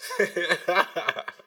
DDW4 ILL G8Z LAUGH.wav